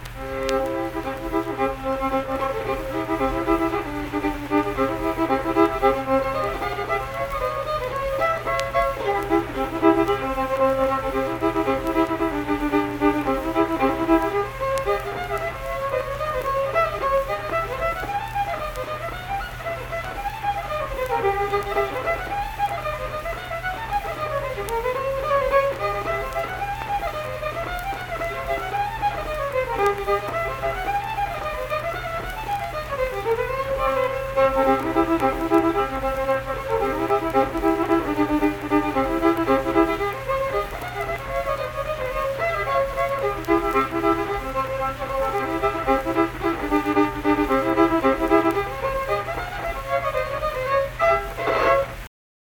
Unaccompanied fiddle music
Instrumental Music
Fiddle
Pleasants County (W. Va.), Saint Marys (W. Va.)